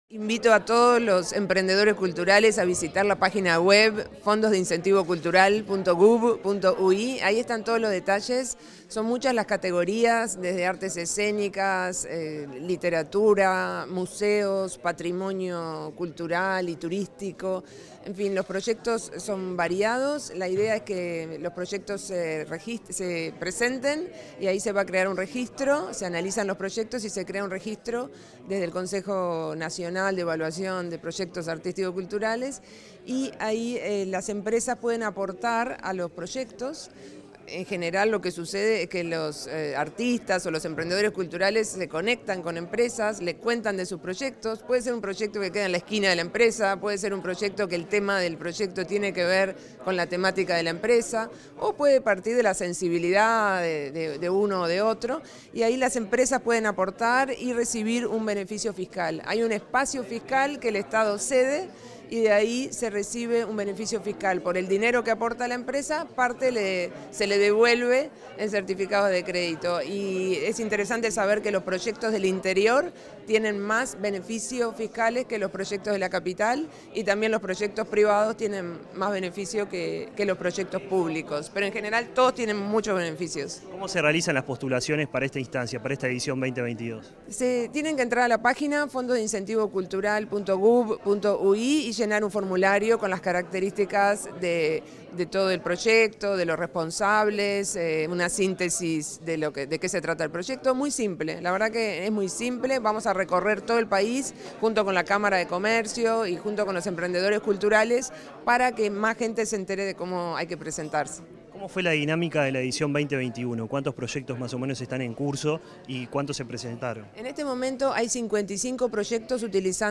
Entrevista a la directora nacional de Cultura, Mariana Wainstein